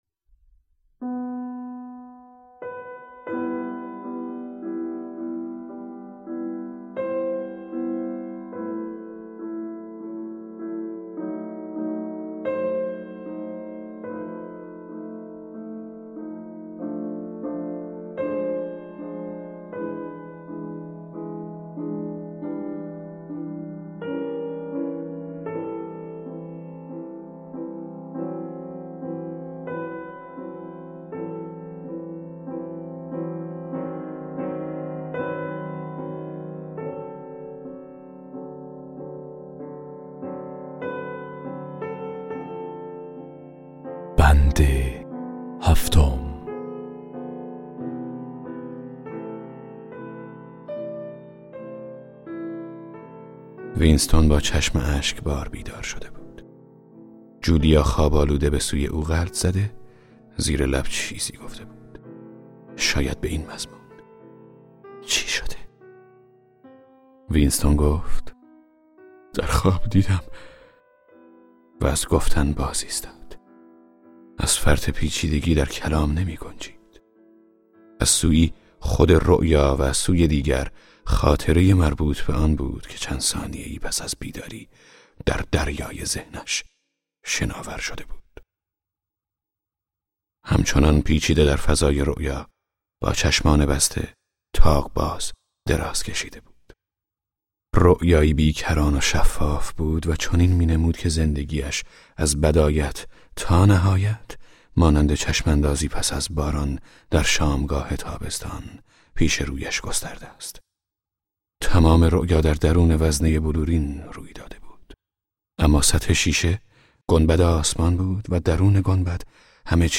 کتاب صوتی 1984 اثر جورج اورول - قسمت 18